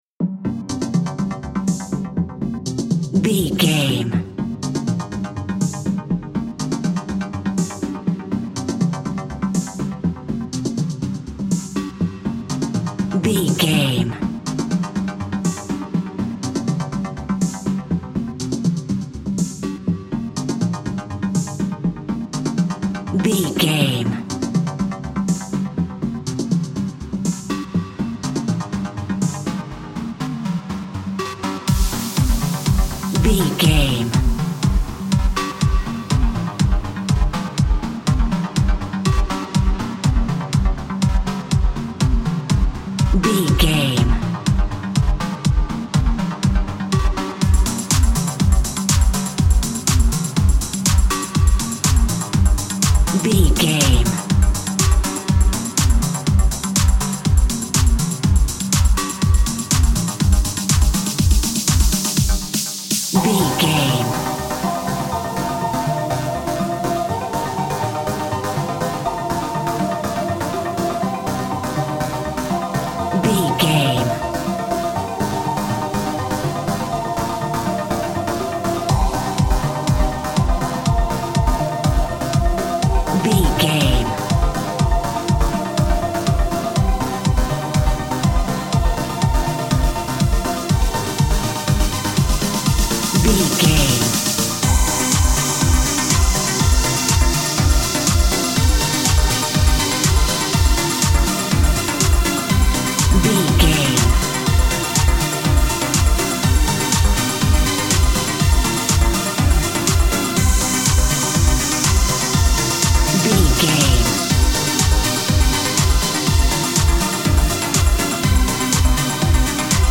Aeolian/Minor
groovy
uplifting
energetic
cheerful/happy
synthesiser
drum machine
house
techno
trance
synth bass
upbeat